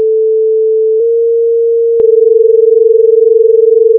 clash2.wav